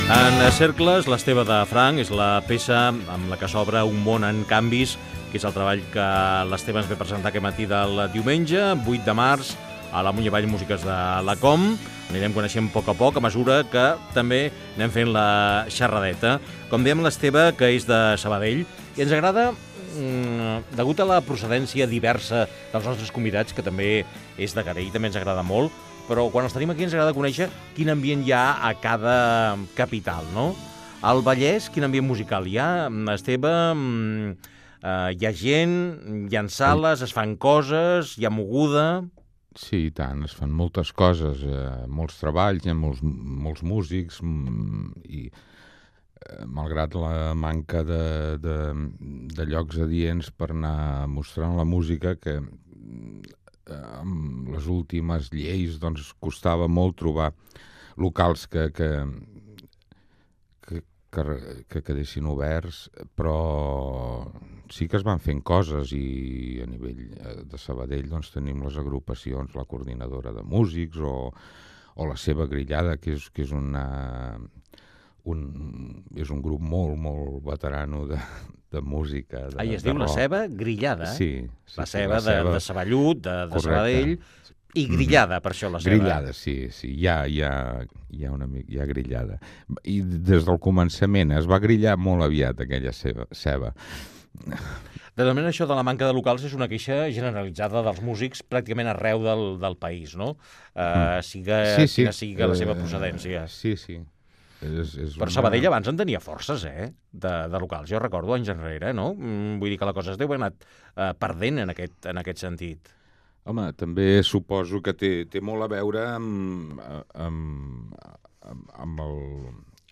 Fragment d'una entrevista